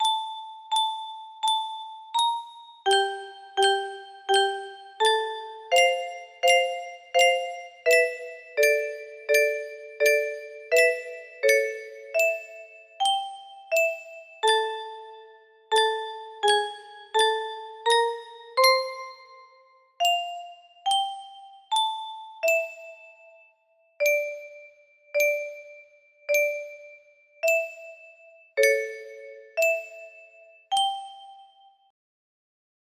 La caja de música de mamá (Mom's music box) music box melody